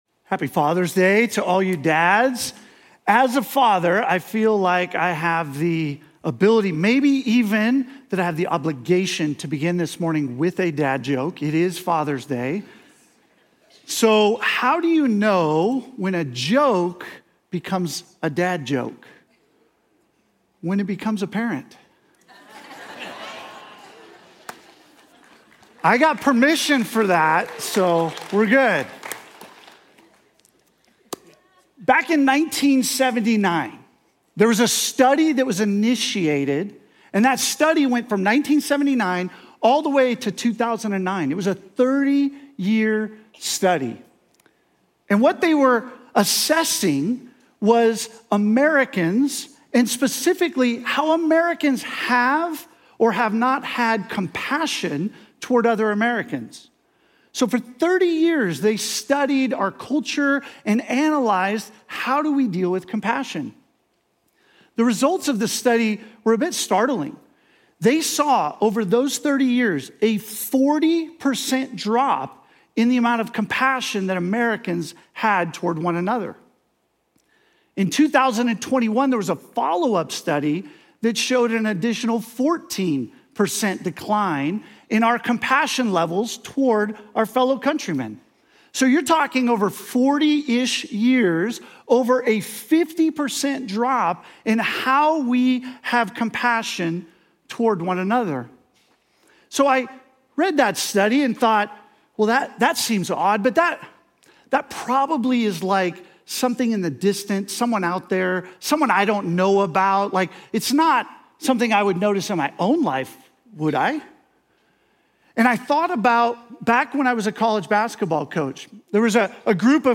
Journey Church Bozeman Sermons Summer In The Psalms: Compassion Jun 15 2025 | 00:36:15 1x 00:00 / 00:36:15 Subscribe Share Apple Podcasts Overcast RSS Feed Share Link Embed